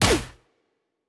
Weapon 02 Shoot 3.wav